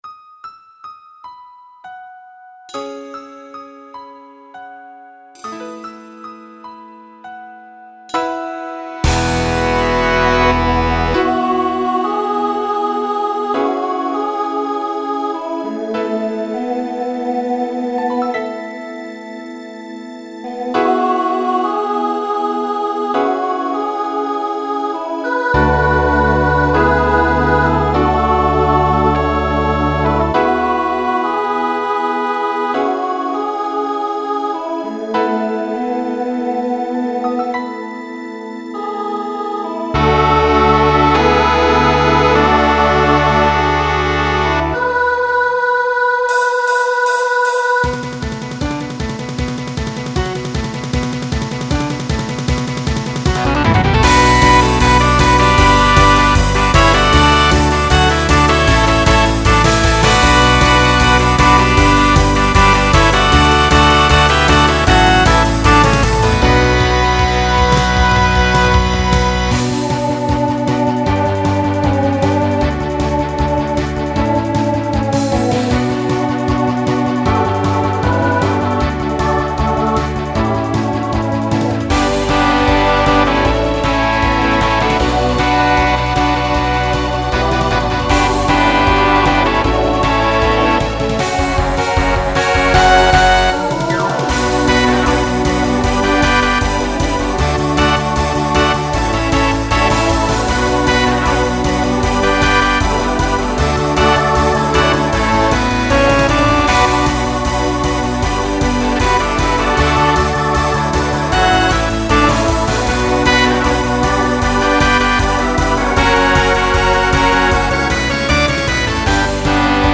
Genre Broadway/Film , Rock Instrumental combo
Voicing SATB